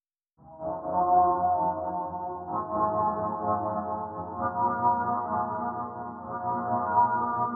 synthe_srx_studio_96.wav